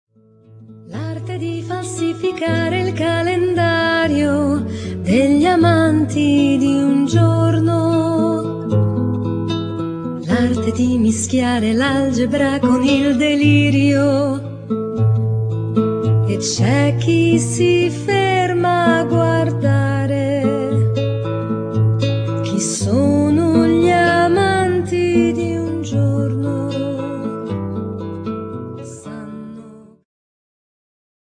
ジャンル Progressive
アコースティック
ローマ出身のアコースティック楽器と女性ヴォーカルによるグループ。
soprano, baritone sax and little flutes
drums and percussions